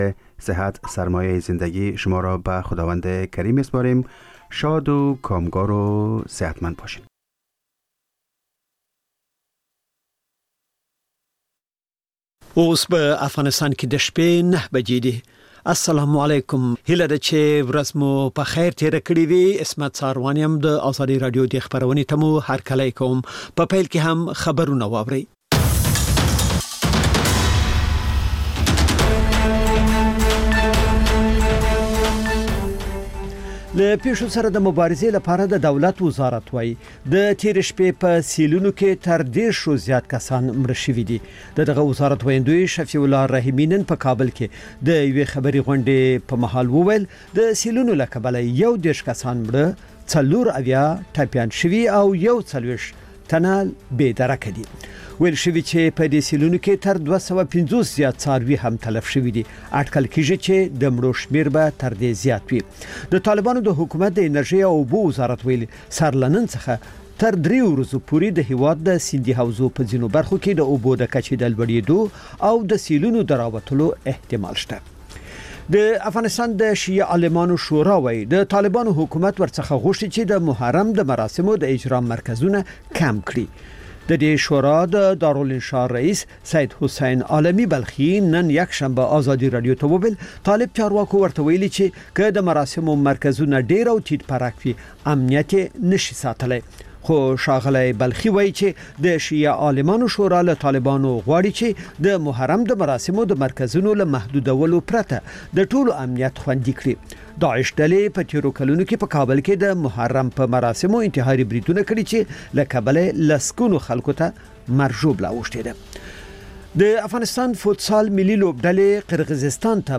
ماخوستنی خبري ساعت